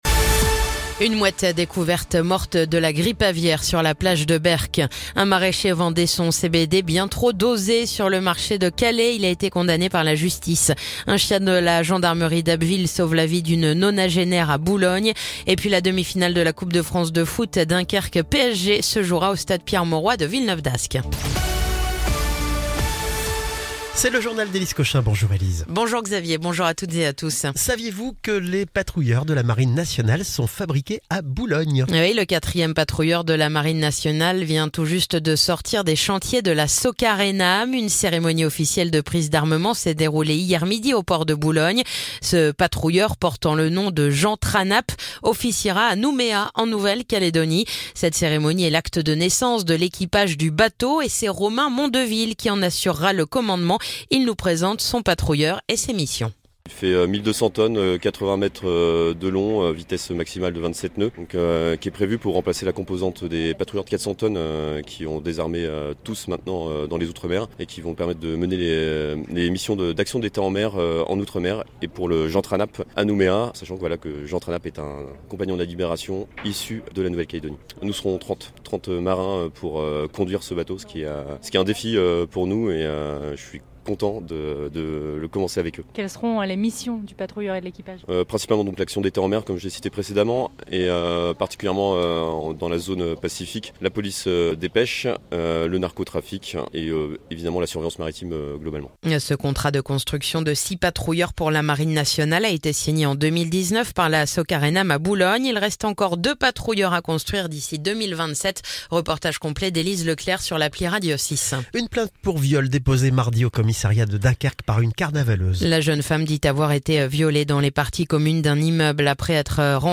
Le journal du jeudi 6 mars